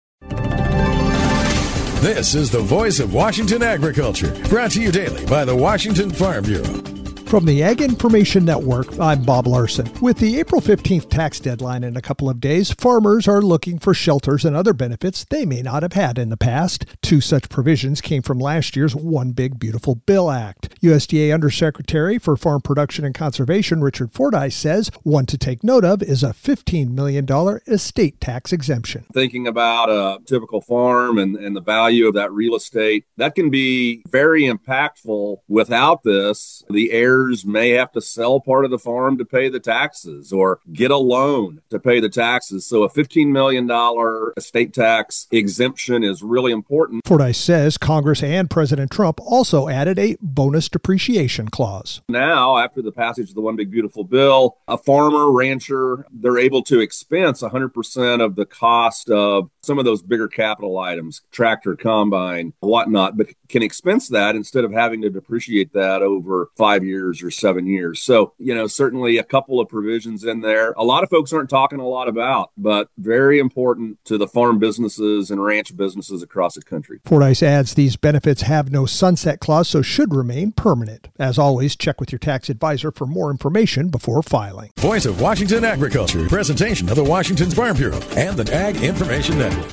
Monday Apr 13th, 2026 47 Views Washington State Farm Bureau Report